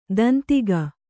♪ dantiga